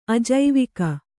♪ ajaivika